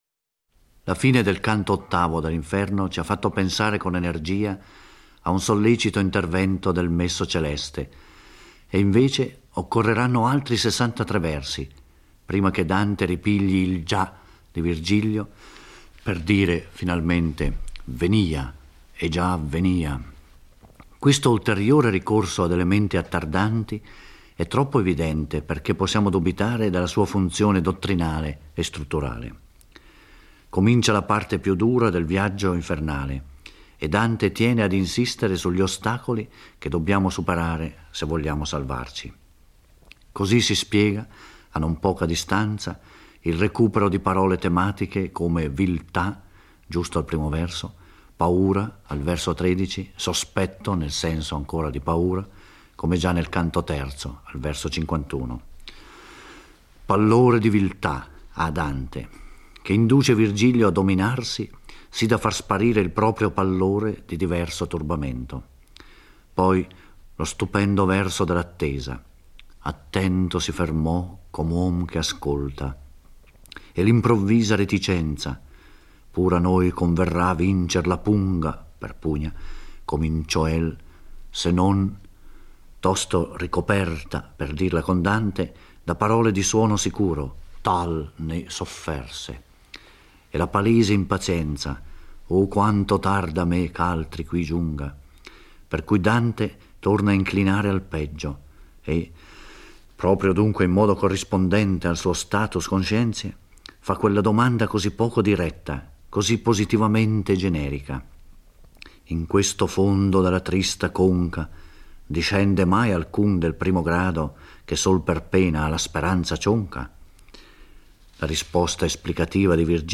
Giorgio Orelli legge e commenta il IX canto dell'Inferno. Qui Dante incontra le tre furie infernali (Megera, Aletto e Tesifone) che invocano Medusa per tramutarlo in pietra.